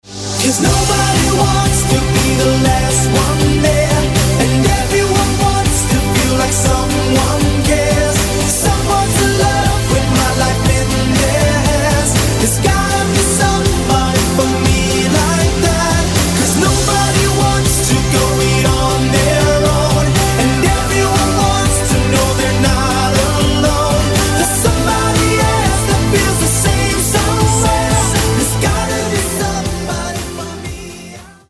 cover verzia